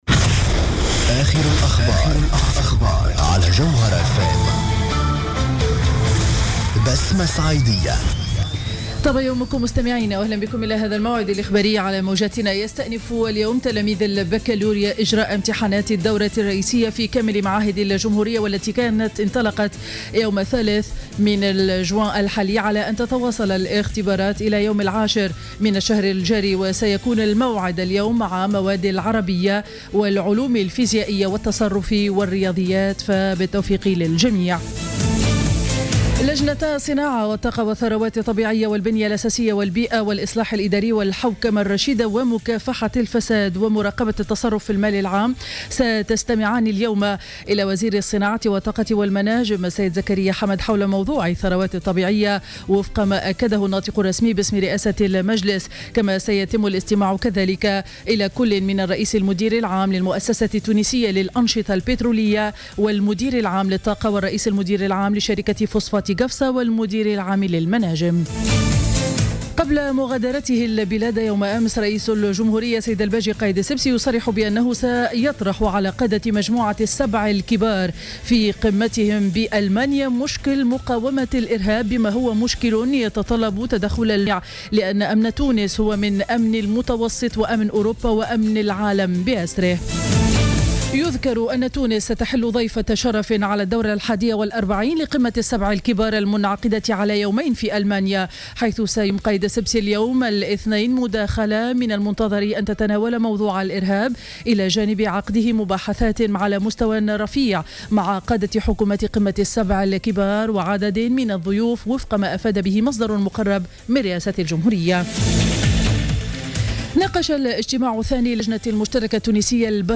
نشرة أخبار السابعة صباحا ليوم الإثنين 08 جوان 2015